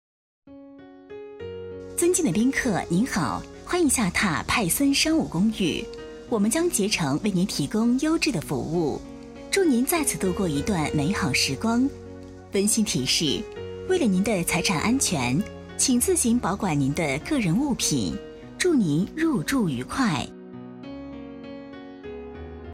女3号配音师
可盐可甜，可沉稳大气，可温柔恬静
代表作品 Nice voices 彩铃 儿童故事 促销 专题片 彩铃-女3-商务酒店.mp3 复制链接 下载